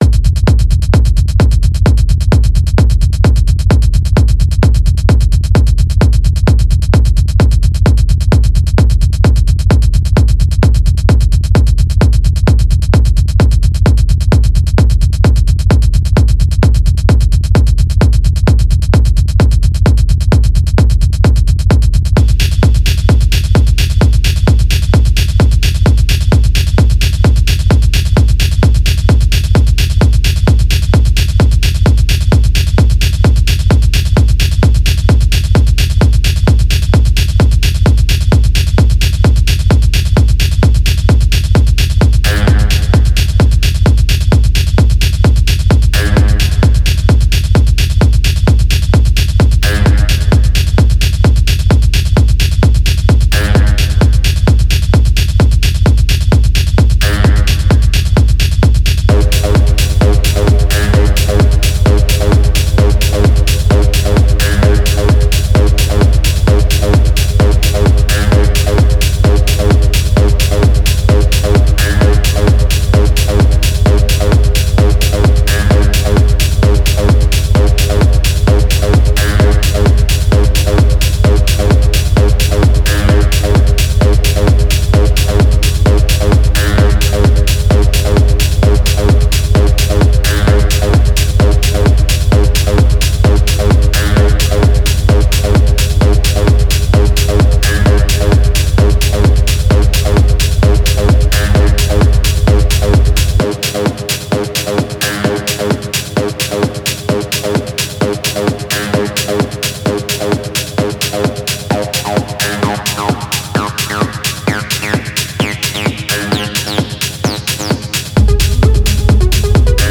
Genre: Electronic, Synthwave.